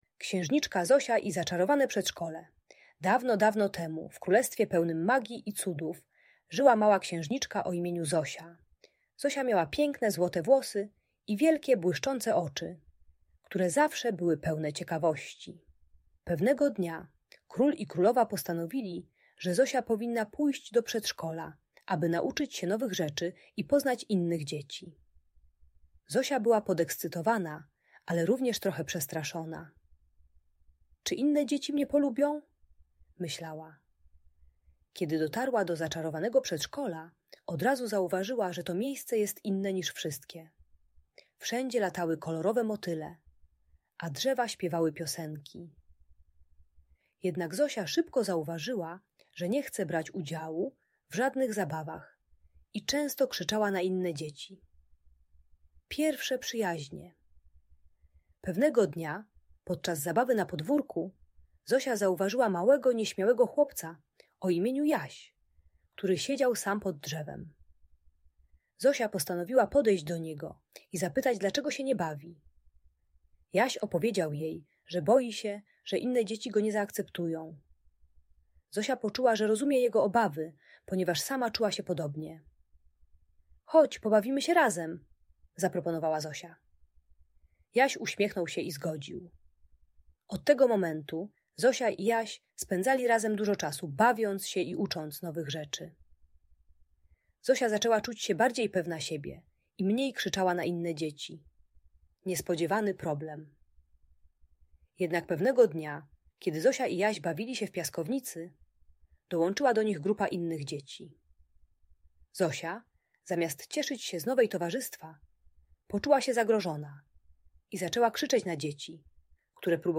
Księżniczka Zosia i Zaczarowane Przedszkole - Audiobajka